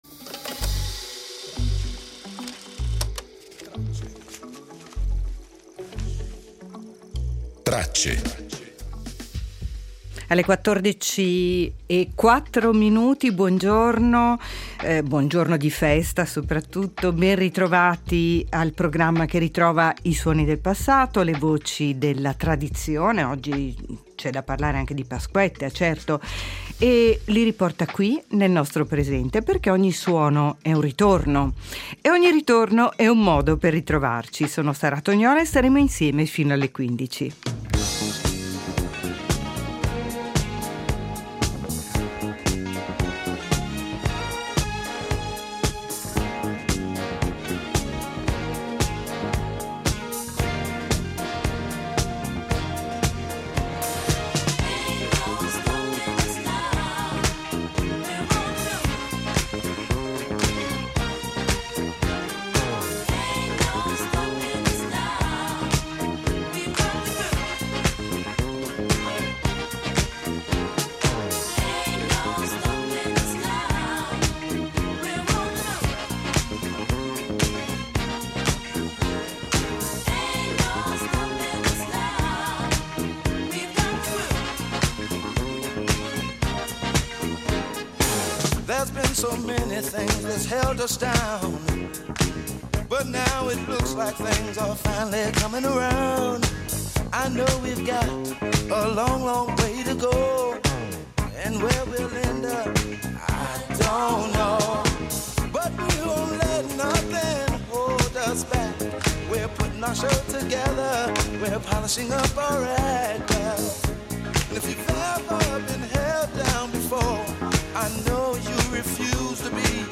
Nella puntata di Pasquetta, Tracce propone un percorso attraverso alcune voci preziose dell’archivio RSI.